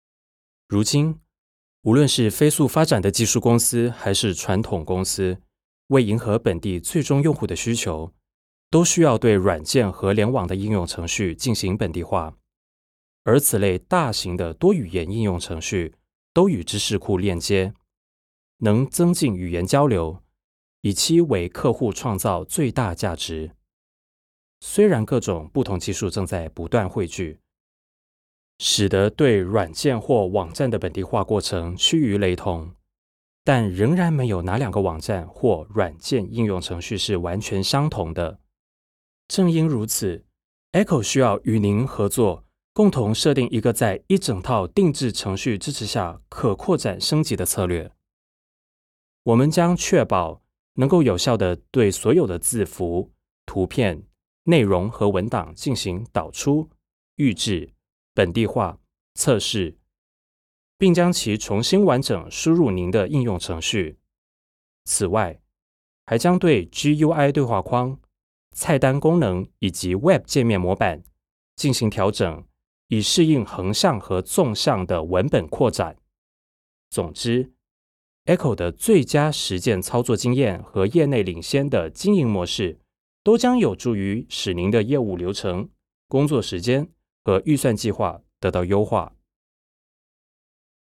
Chinese (Mainland) voiceover